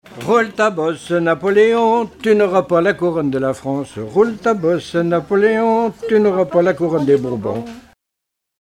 branle
Couplets à danser
Répertoire de chansons populaires et traditionnelles
Pièce musicale inédite